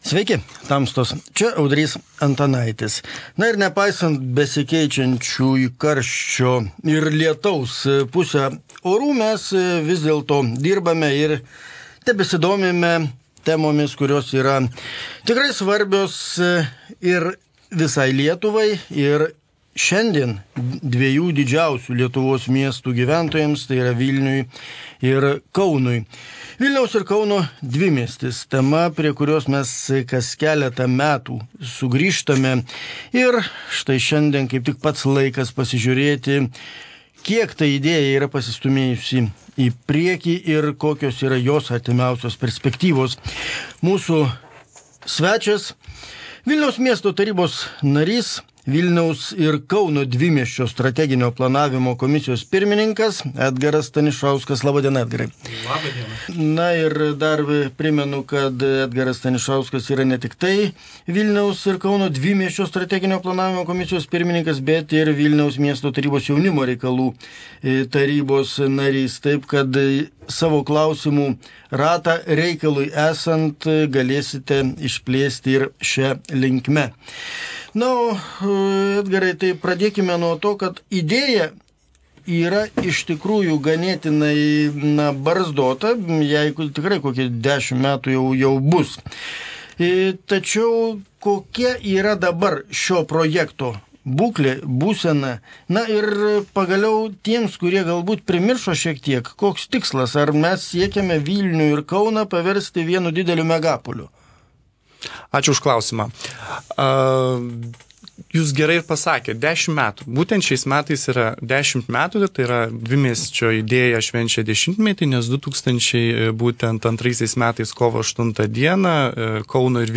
Pokalbis su Vilniaus miesto tarybos nariu Edgaru Stanišausku.